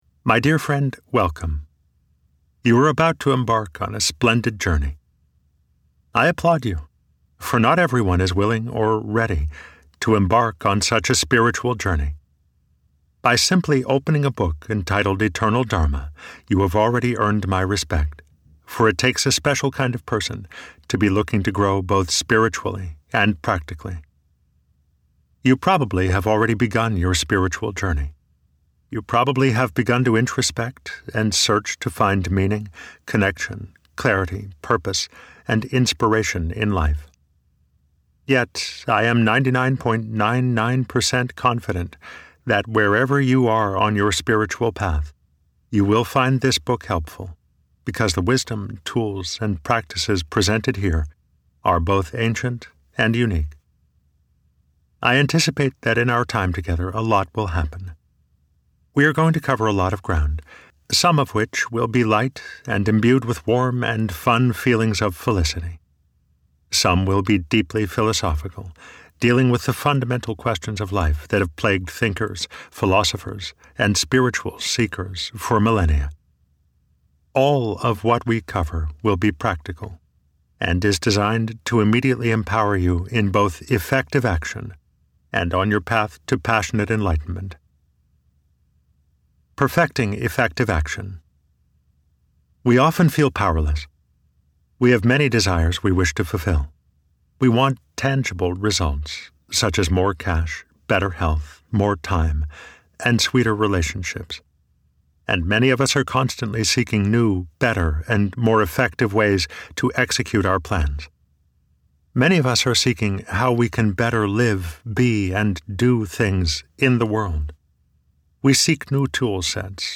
Eternal Dharma - Vibrance Press Audiobooks - Vibrance Press Audiobooks